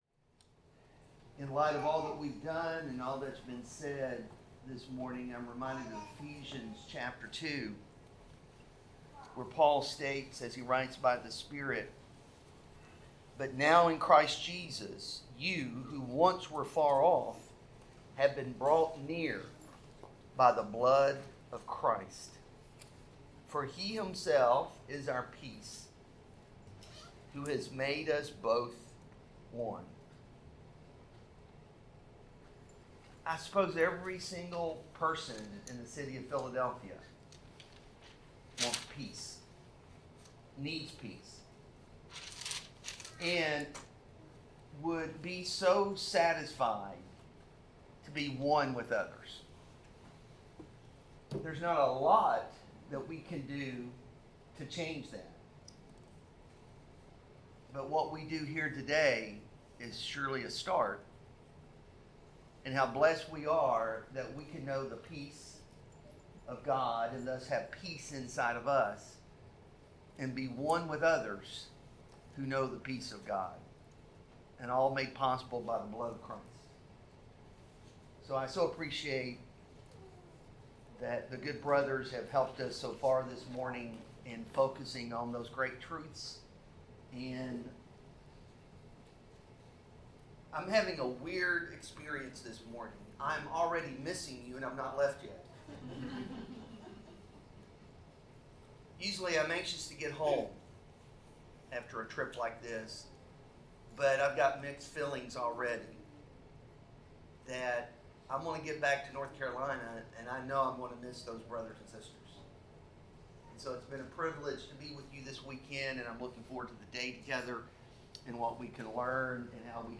Passage: Matthew 5:13-16; 1 Timothy; 2 Timothy 2:24-26; 2 Timothy 4:2-3 Service Type: Sermon